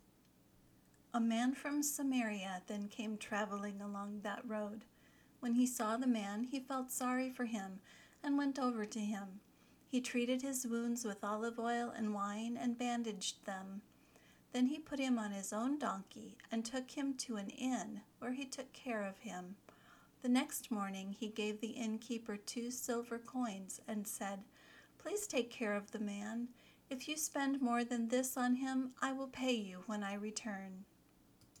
Try to imitate the speaker’s intonation, the rhythm of her speech, and the stresses she puts on words and syllables.